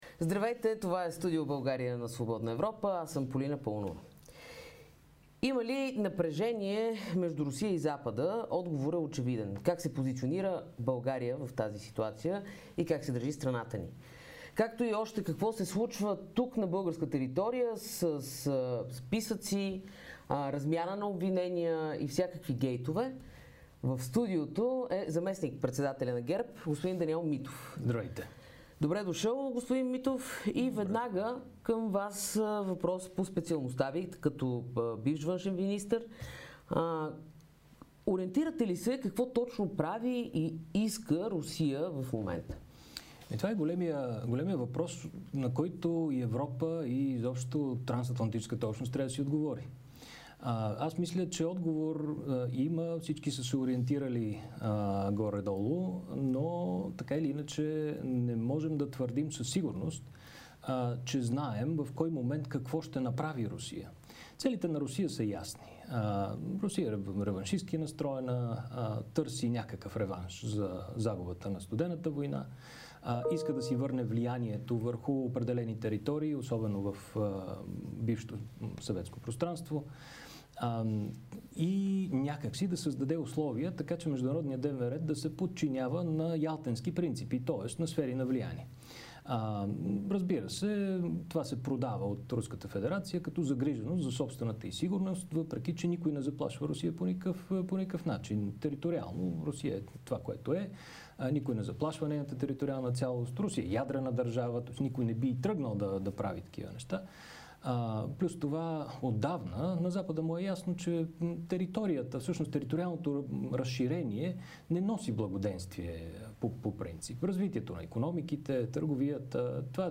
Бившият външен министър и настоящ зам.-председател на ГЕРБ Даниел Митов каза, че Русия не само иска да създаде архитектура на международните отношенията от ялтенски тип, а иска да дестабилизира демокрациите.